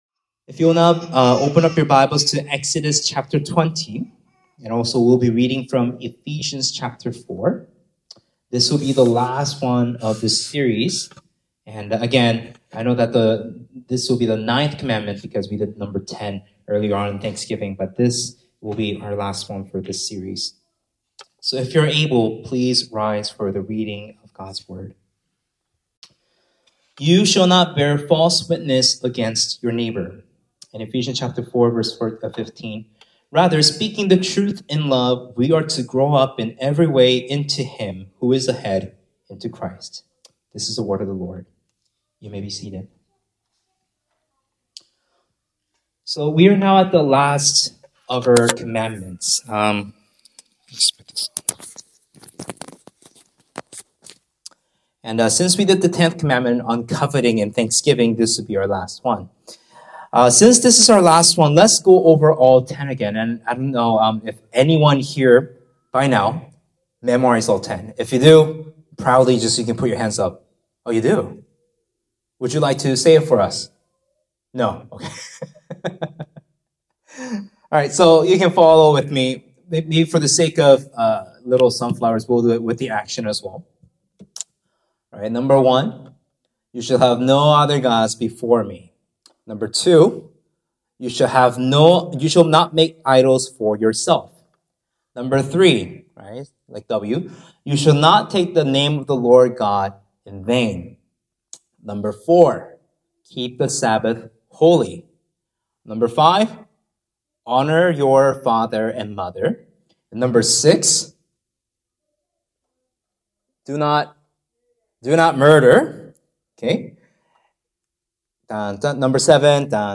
Sermons | Sonflower Community Church